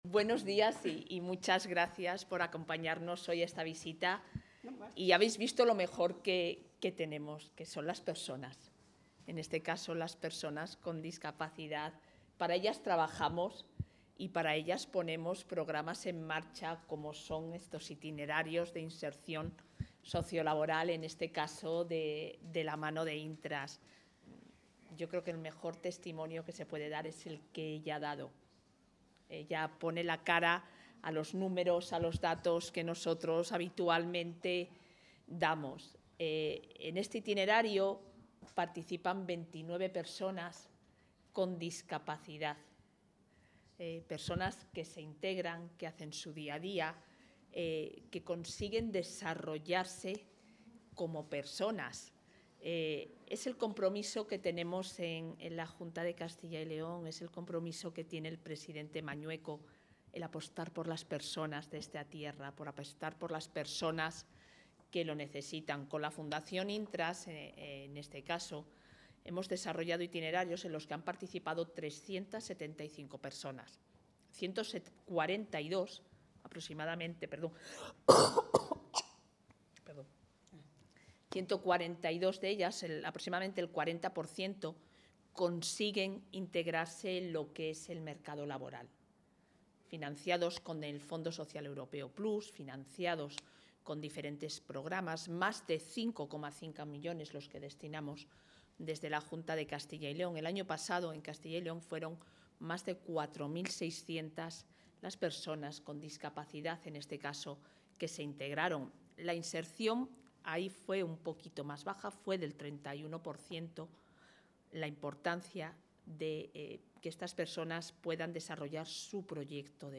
Intervención de la vicepresidenta.